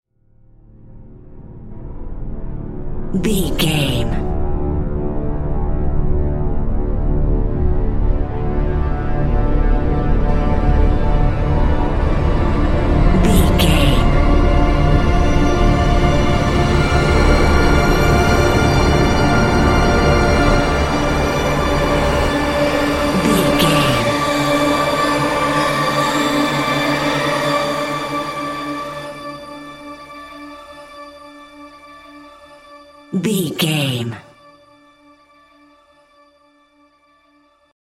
Short Stinger.
Aeolian/Minor
B♭
tension
ominous
dark
suspense
eerie
strings
brass
synth
pads